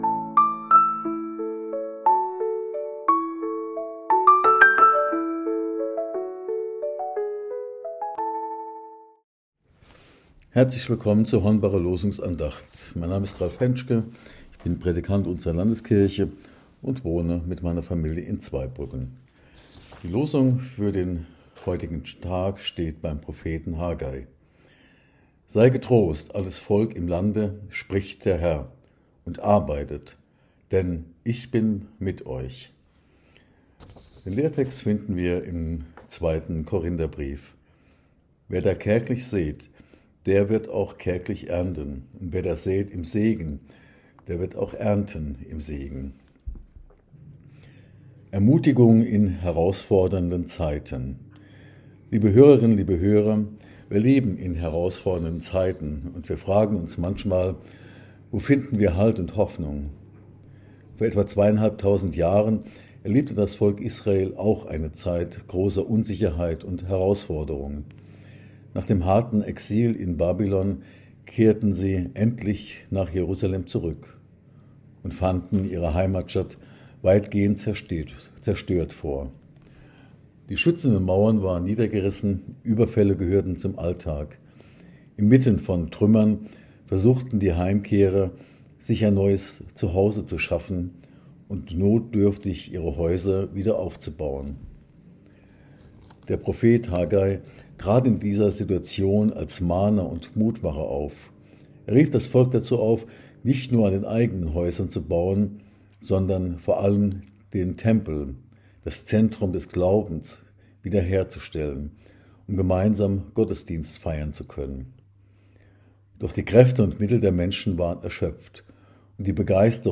Losungsandacht für Dienstag, 23.12.2025 – Prot.